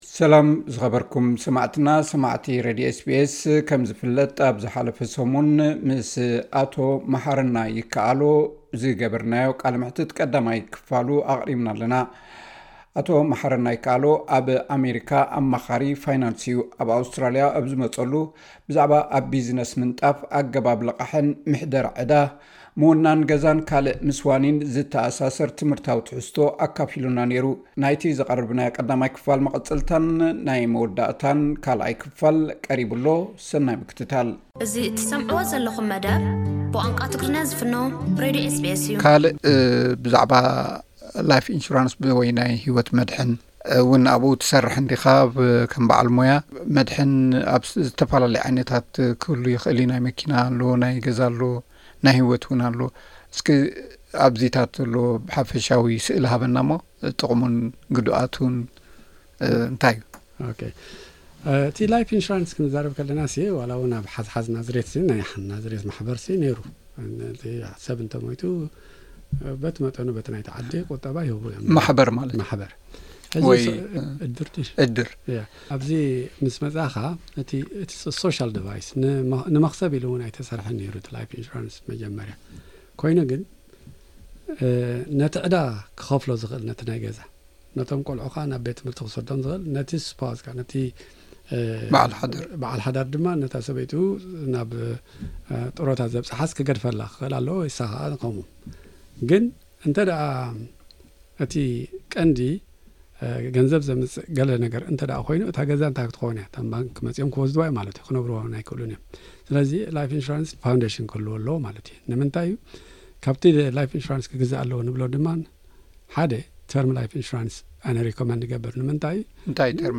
ቃለመሕትት